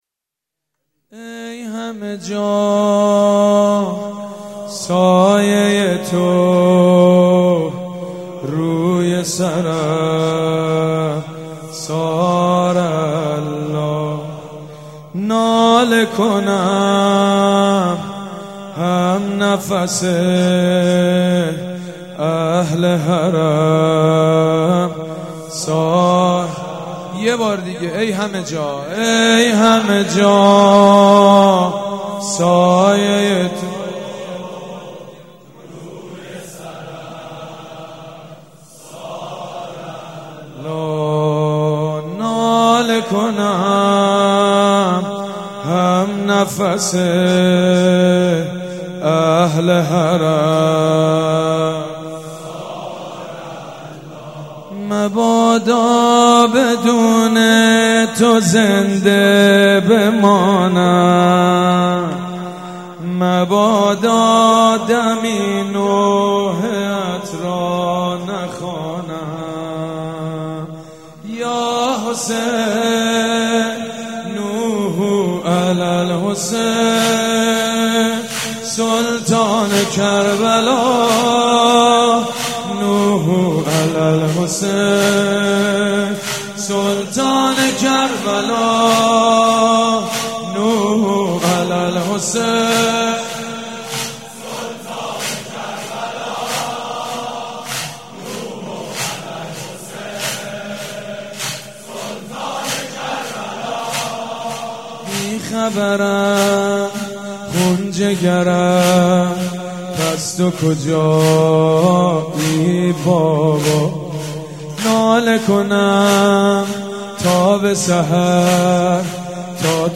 صوت مراسم شب سوم محرم ۱۴۳۷هیئت ریحانه الحسین(ع) ذیلاً می‌آید: